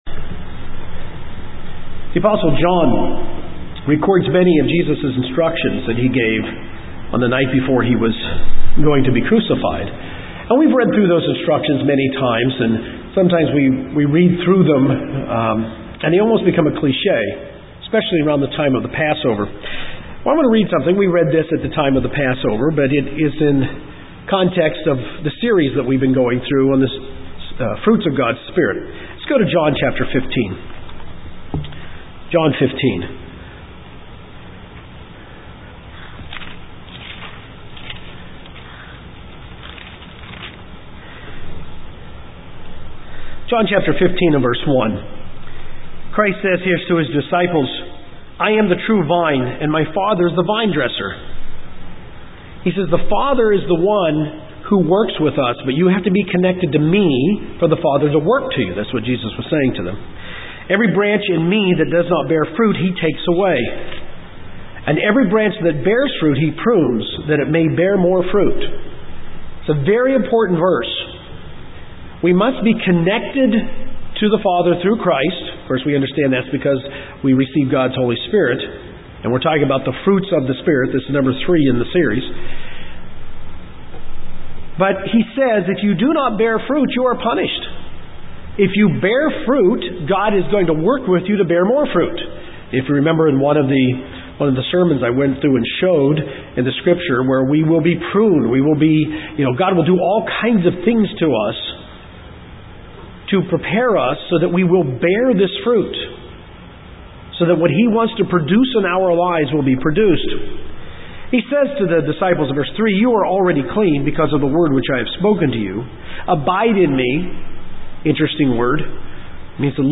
This is the third in a series of sermons on the fruits of the spirit. The series is beginning at the end of the list of fruits in Galatians 5:22-23.